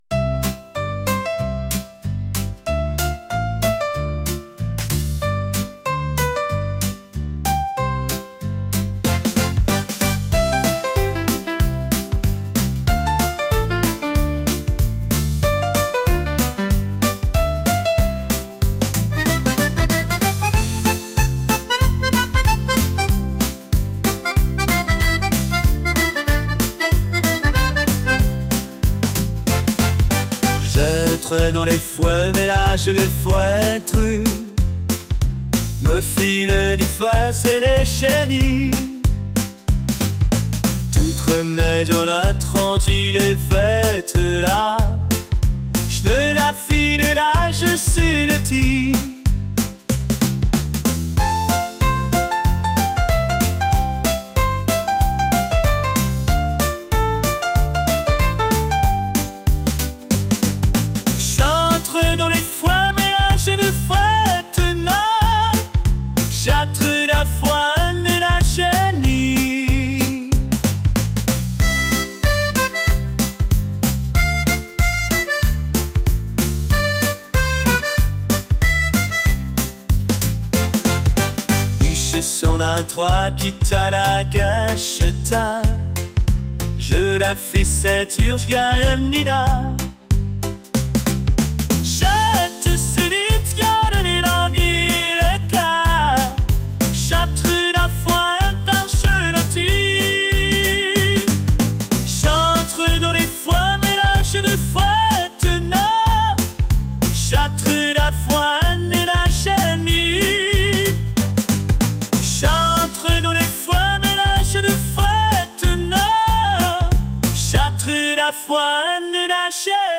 catchy | pop